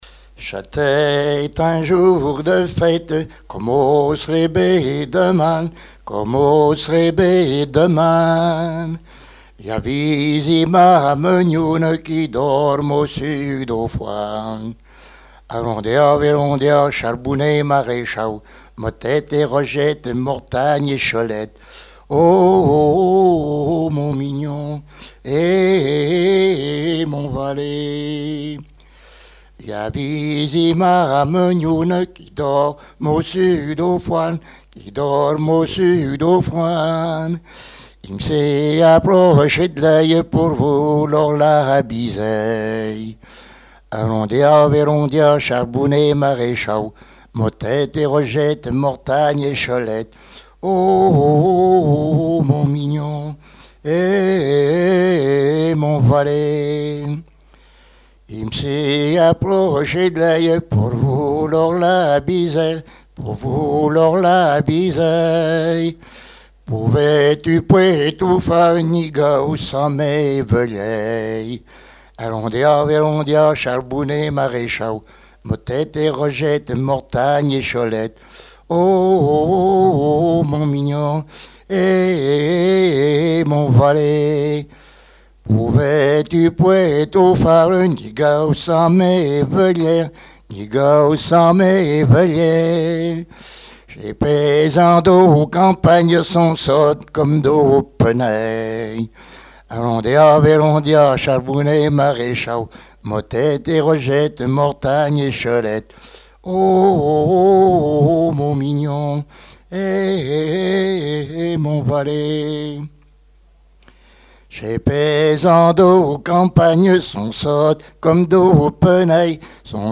Saint-Hilaire-de-Riez
interprétation de chansons traditionnelles et populaires
Pièce musicale inédite